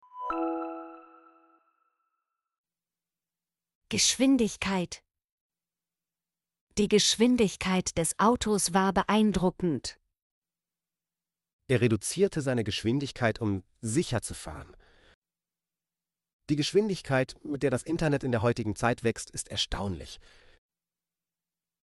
geschwindigkeit - Example Sentences & Pronunciation, German Frequency List